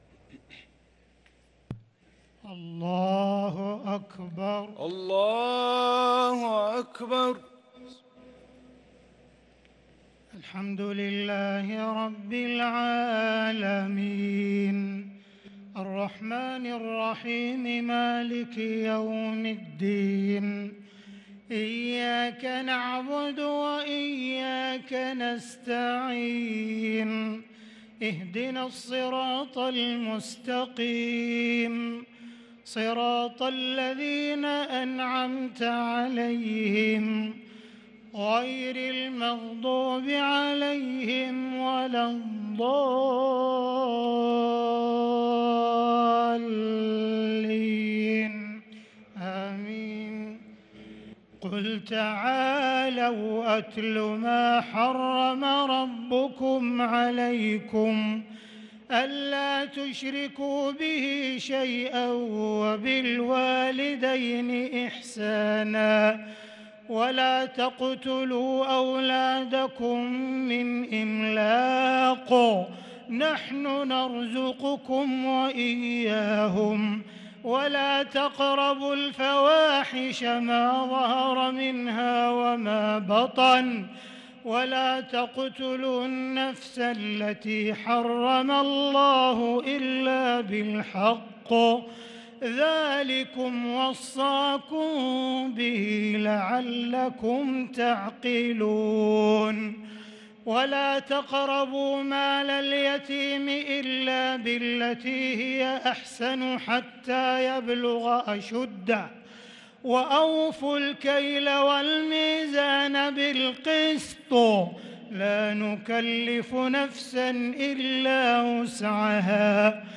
صلاة التراويح ليلة 10 رمضان 1443 للقارئ عبدالرحمن السديس - التسليمة الأخيرة صلاة التراويح